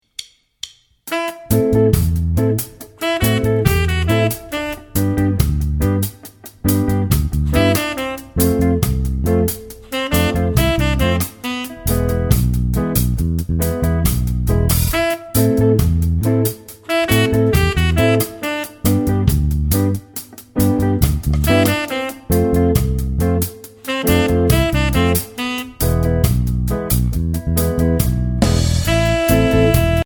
Voicing: Bass Method